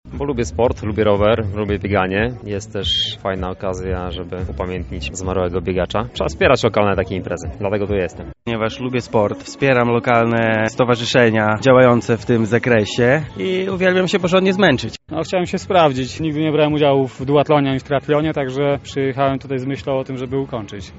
Tak odpowiadali uczestnicy biegu zapytani o powody wzięcia udziału w imprezie.